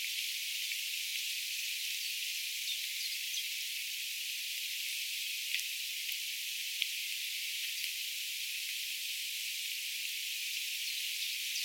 jokin uusi heinäsirkkalaji
tuollainen_heinasirkan_laulu.mp3